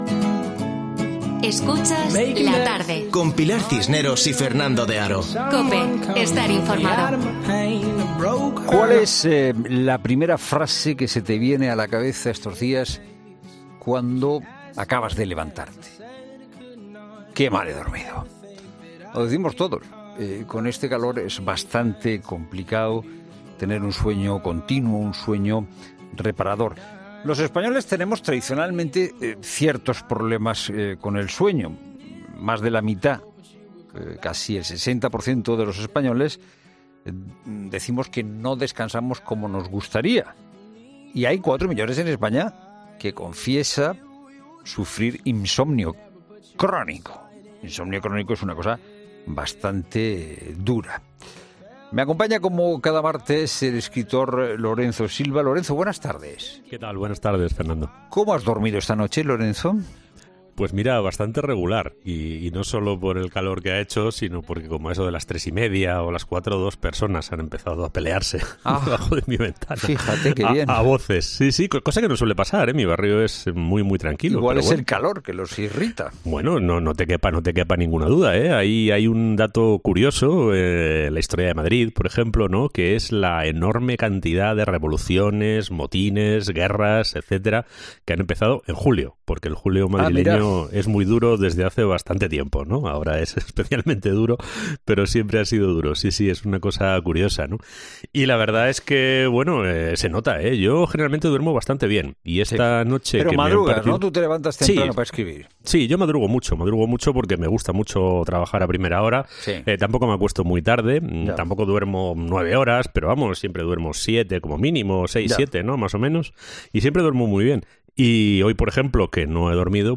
En La Tarde hablamos con el escritor